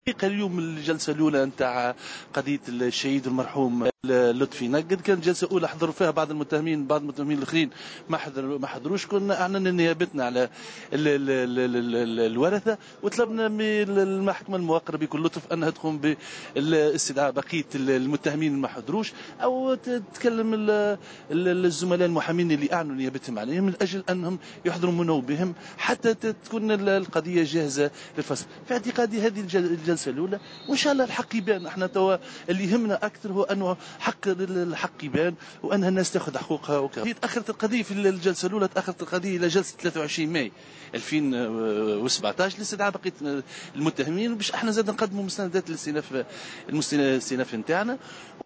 في تصريح ل"الجوهرة أف ام".